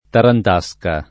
Tarantasca [ tarant #S ka ]